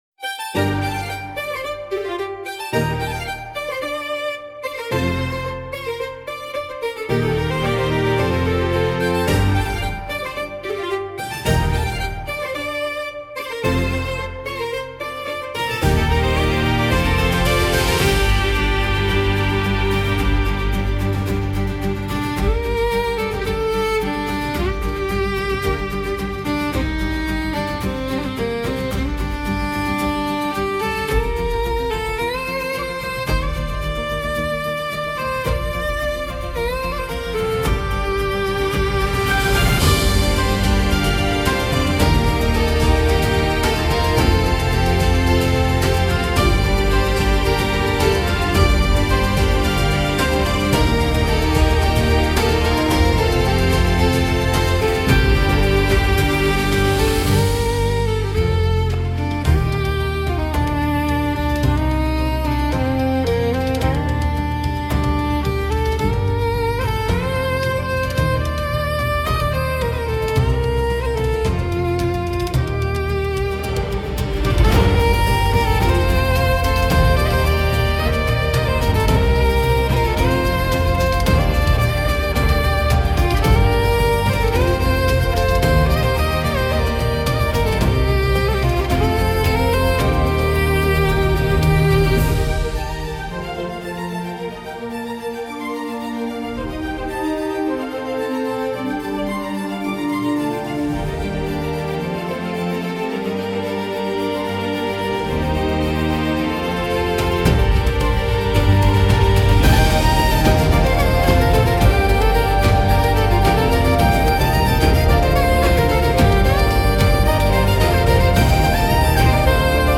BGM / Instrumental